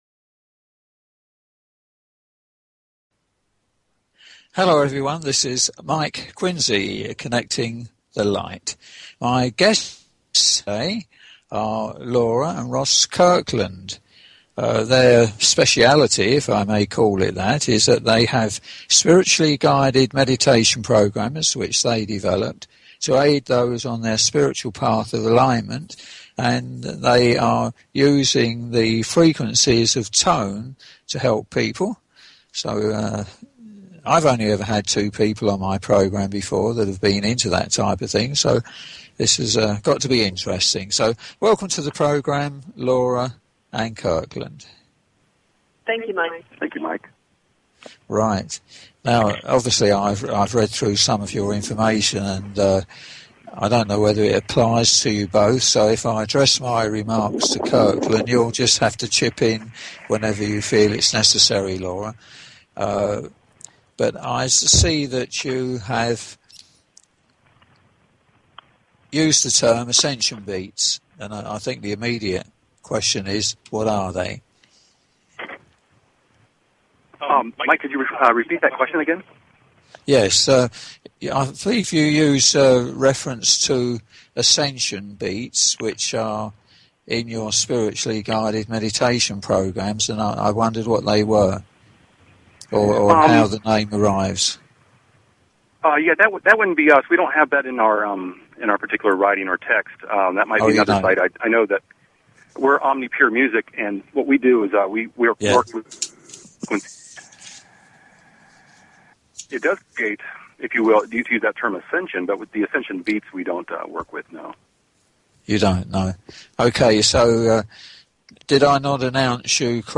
Talk Show Episode, Audio Podcast, Connecting_The_Light and Courtesy of BBS Radio on , show guests , about , categorized as
During the interview some short clips of their music were played.